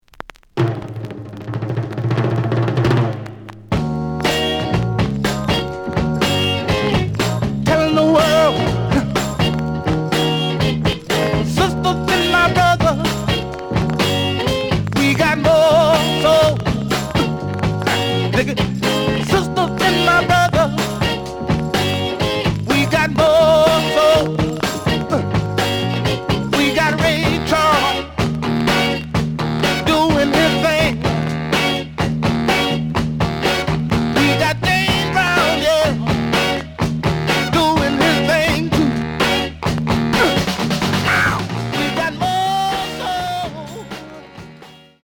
The audio sample is recorded from the actual item.
●Genre: Funk, 60's Funk
Some click noise on both sides due to scratches.)